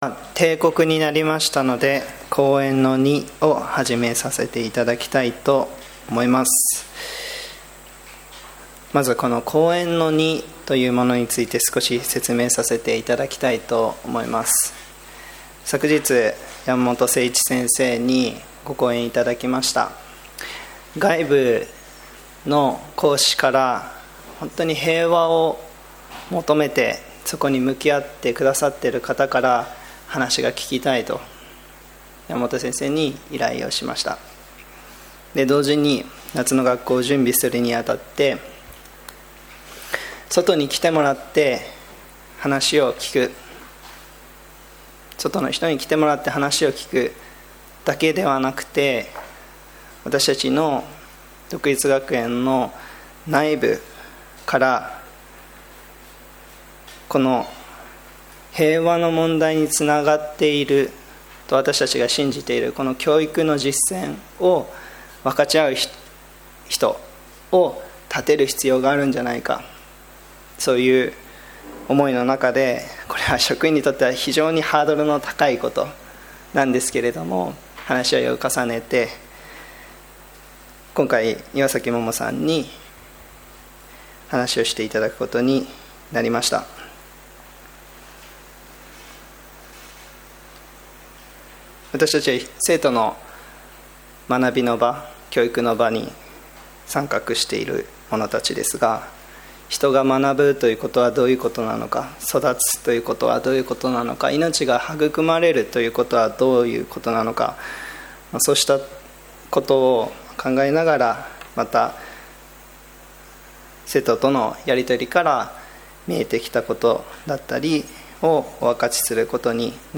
講演Ⅱ